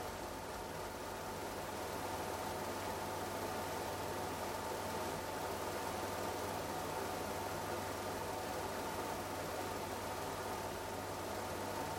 Strangely enough, the 125 Hz frequency is way lower than the others, at 25% PWM speed.
I have recorded the signals shown above, but please keep in mind that I’ve enabled Automatic Gain Control (AGC) to do so to make it easier for you to reproduce them.
25% Fan Speed